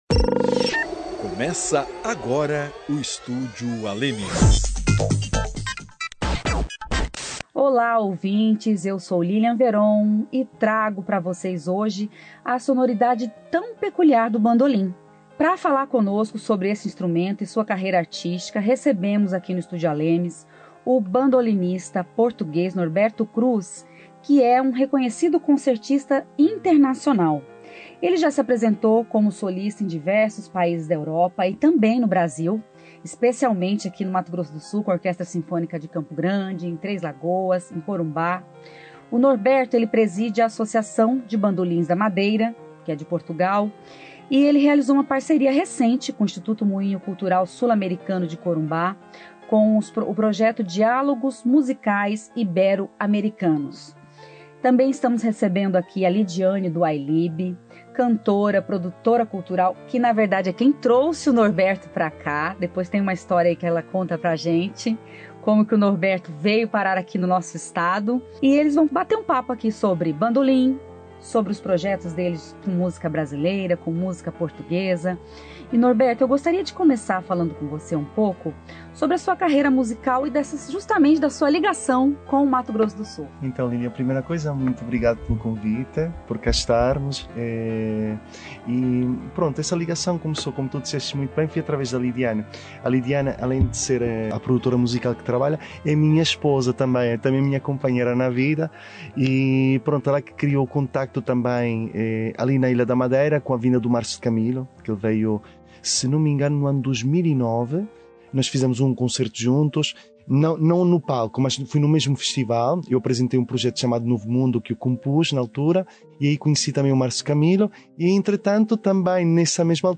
trazem a sonoridade do bandolim e o encontro com a música brasileira.